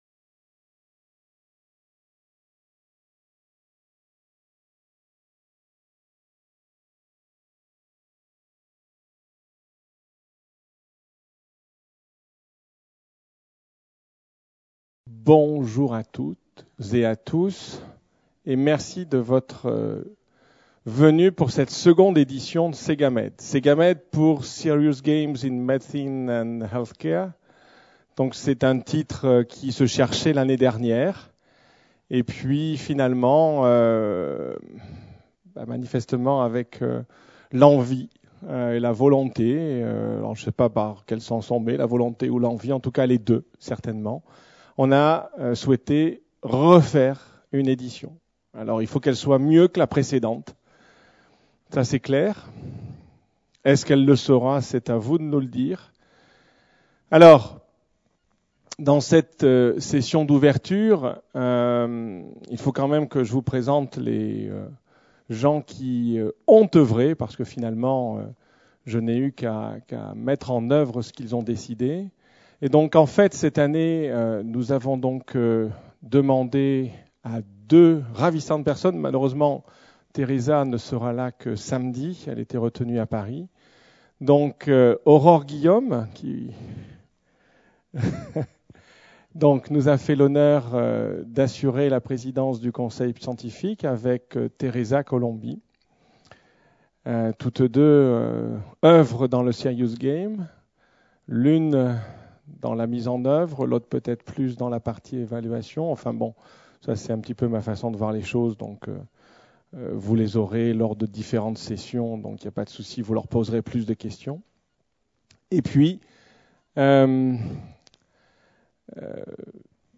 Conférence enregistrée dans le cadre de la 2ème conférence nationale sur l’usage des jeux sérieux dans l’enseignement et l’apprentissage en médecine et en santé (SeGaMed 2013) à Nice les 12 et 13 septembre 2013.